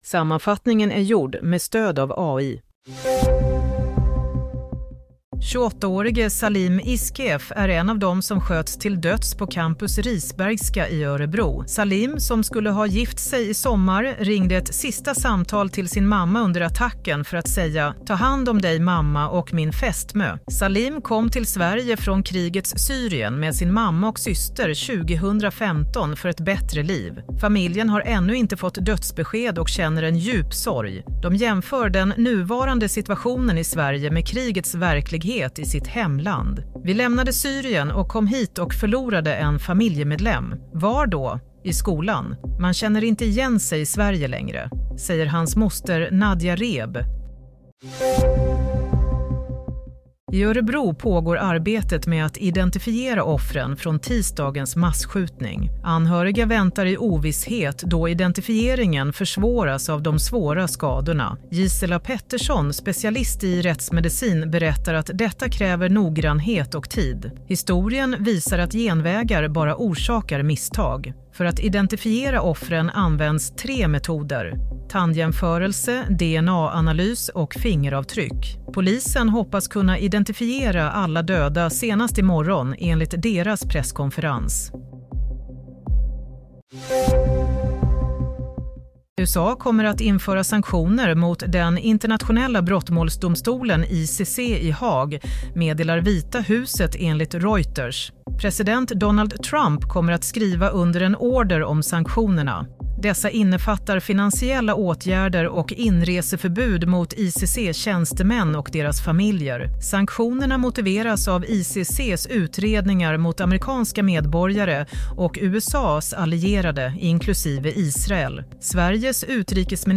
Play - Nyhetssammanfattning – 6 februari 22:00